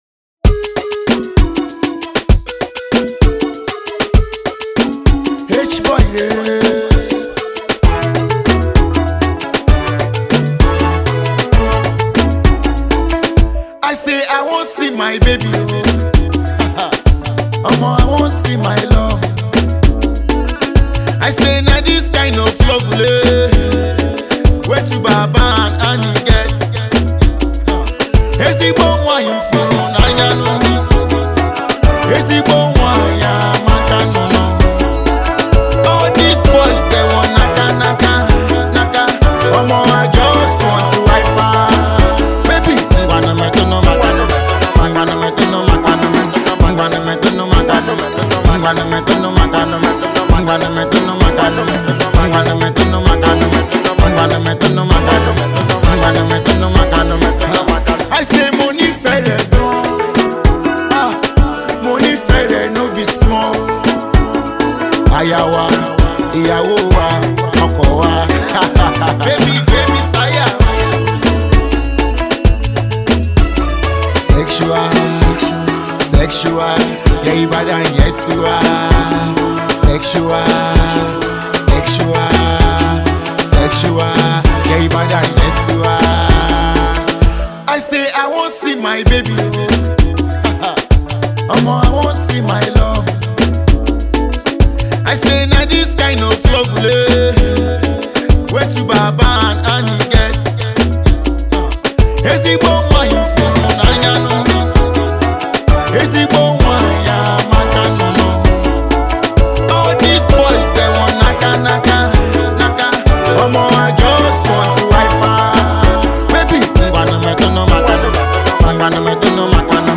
Pop
The mid-tempo cut
groovy strings driven instrumentation inspired by high-life